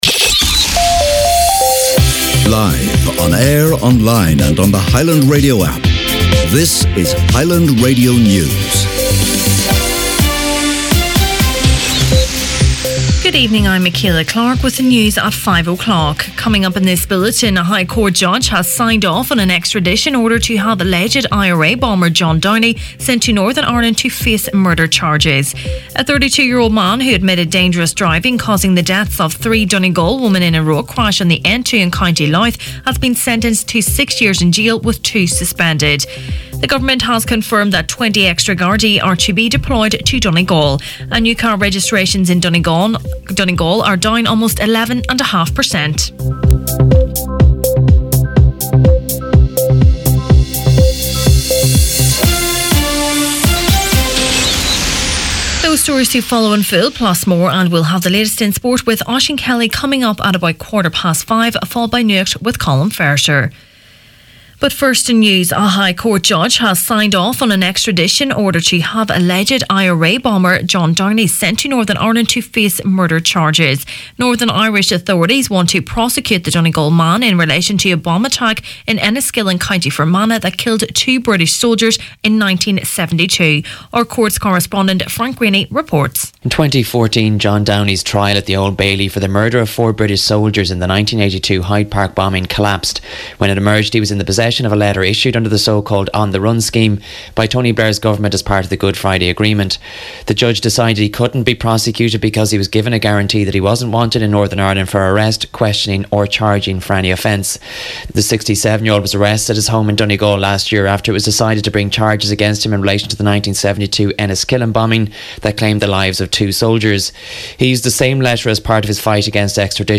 Main Evening News, Sport, Nuacht and Obituaries Friday March 1st